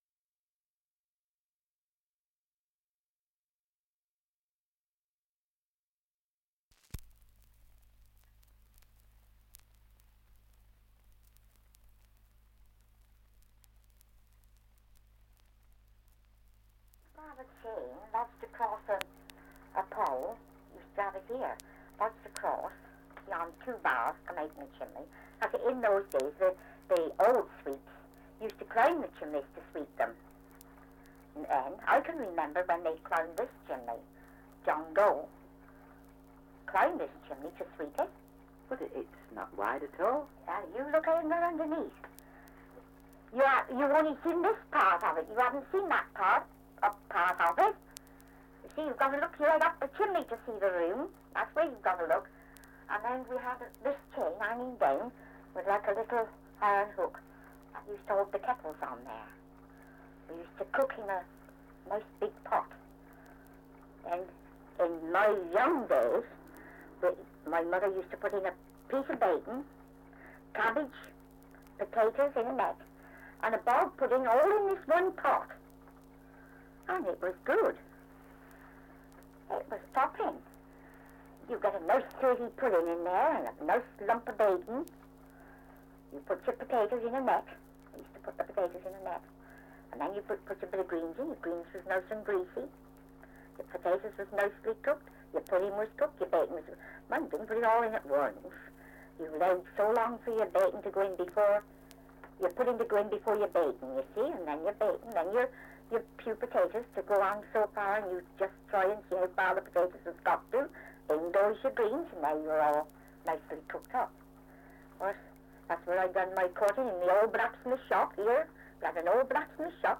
Survey of English Dialects recording in Eynsham, Oxfordshire
78 r.p.m., cellulose nitrate on aluminium